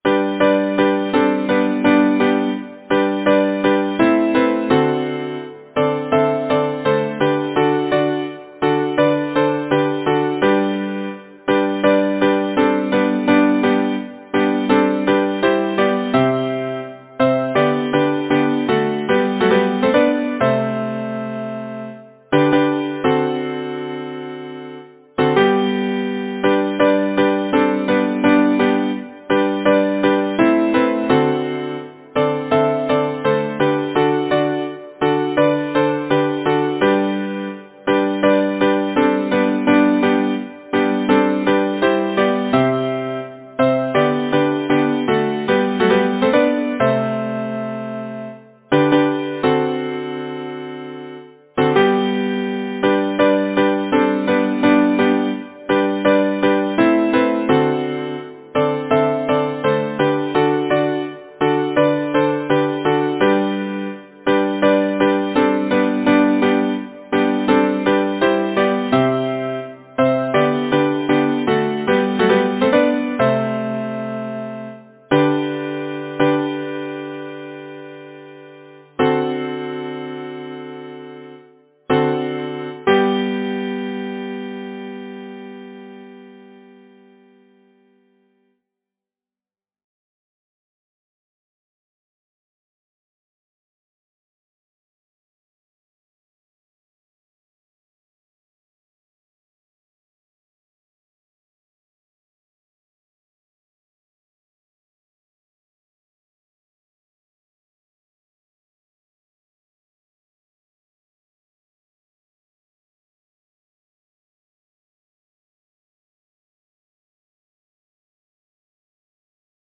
Title: October’s party Composer: Flora Ellis Wells Lyricist: George Cooper Number of voices: 4vv Voicing: SATB Genre: Secular, Partsong
Language: English Instruments: A cappella